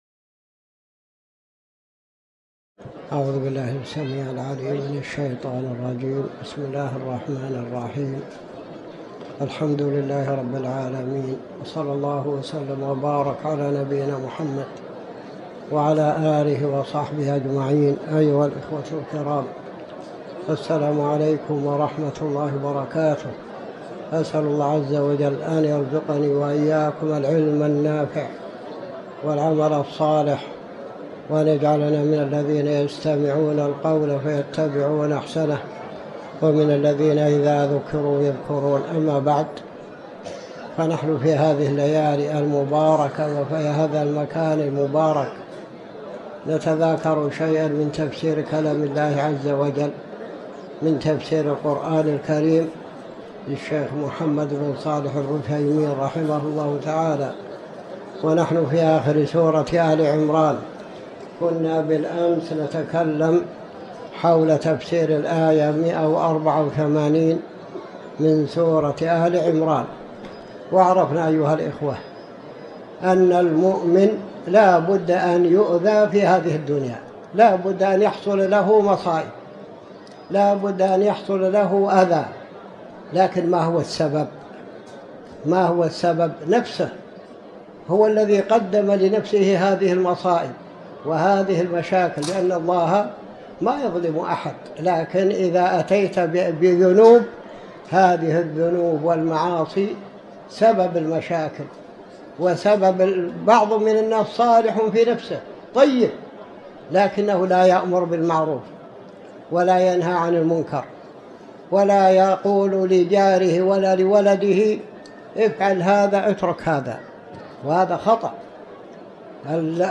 تاريخ النشر ١٢ رجب ١٤٤٠ هـ المكان: المسجد الحرام الشيخ